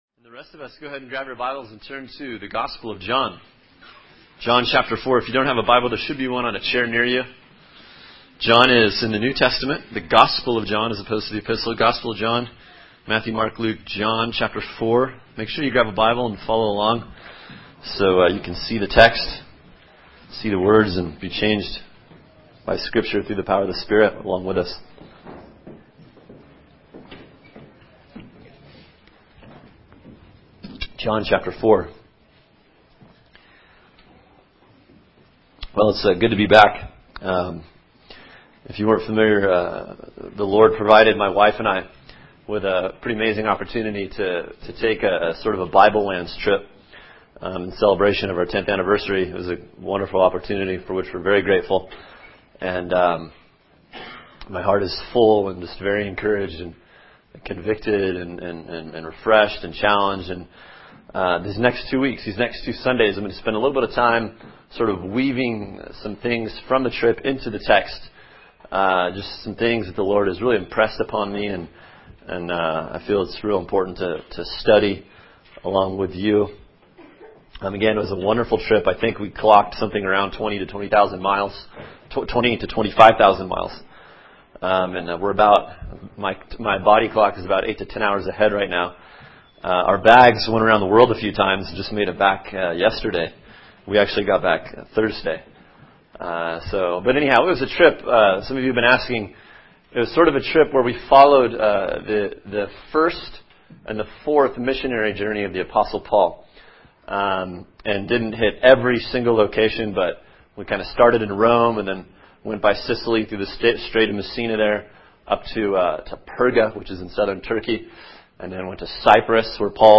[sermon] John 4:1-26 “Where We Meet God” | Cornerstone Church - Jackson Hole